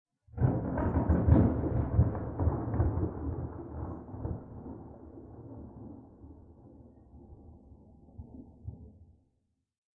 描述：2017年5月20日，雷暴在匈牙利Pécel后面发生雷暴，倾盆大雨但闪电微弱。
由SONY ICDUX512录制。
Tag: 风暴 气候 雷电 暴雨 雷暴 自然 现场录音